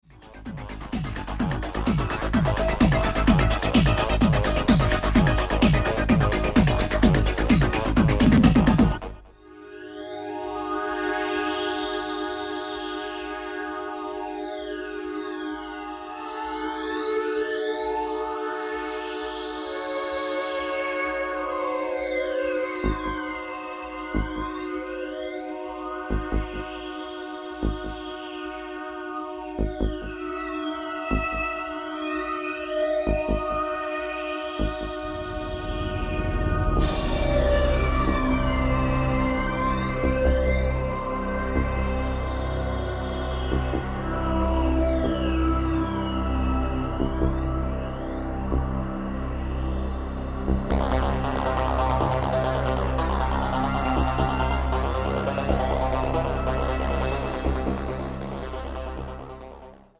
Style: Goa Trance